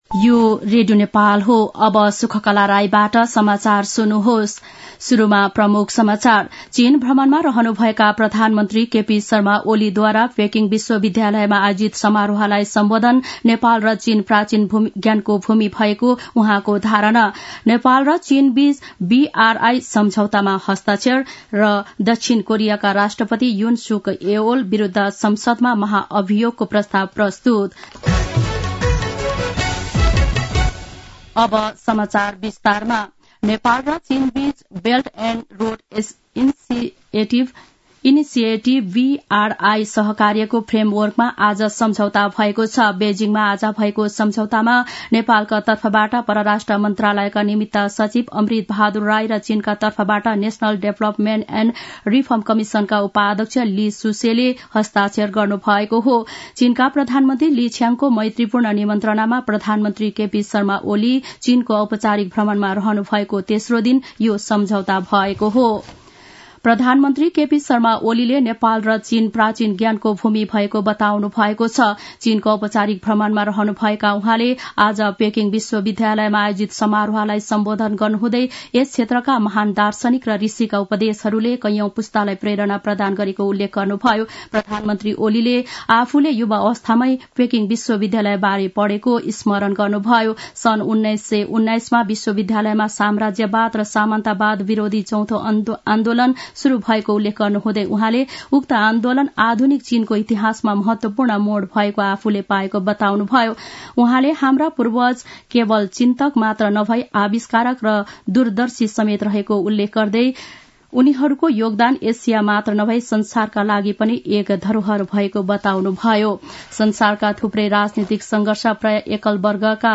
दिउँसो ३ बजेको नेपाली समाचार : २० मंसिर , २०८१
3-pm-nepali-news-1-3.mp3